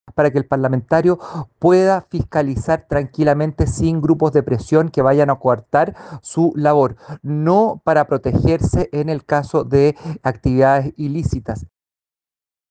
Palabras que fueron secundadas por el RN José Miguel Castro, quien además sostuvo que el fuero responde a los procesos de fiscalización internos del Parlamento.